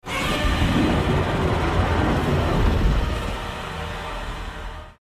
Звуки Годзиллы
Здесь вы найдете его мощный рев, грохот шагов, звуки разрушений и другие эффекты из фильмов и комиксов.
Грозное рычание Годзиллы-монстра